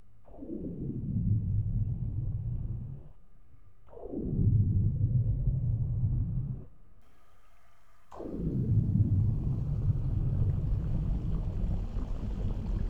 Index of /90_sSampleCDs/E-MU Producer Series Vol. 3 – Hollywood Sound Effects/Water/UnderwaterDiving
UNDERWATE01R.wav